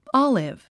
オリーブは「オリブ」と発音しましょう。